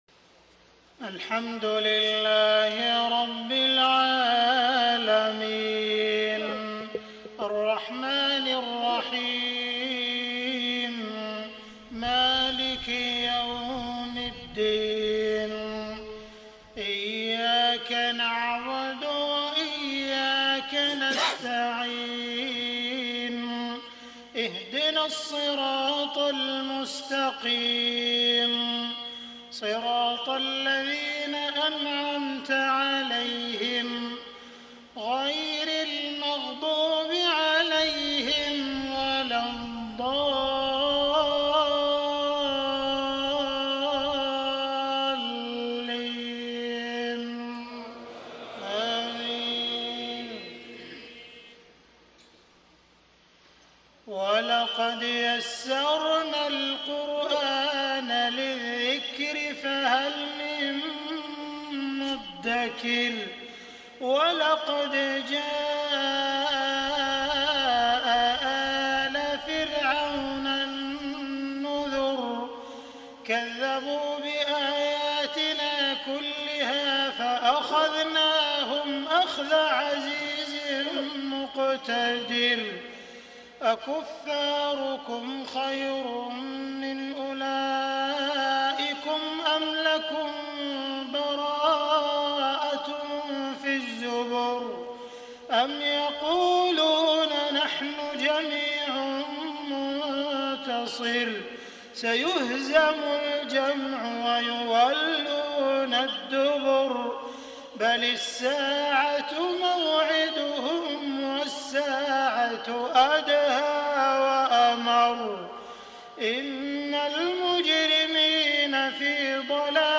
صلاة المغرب ١٠ محرم ١٤٣٤هـ من سورة القمر | > 1434 🕋 > الفروض - تلاوات الحرمين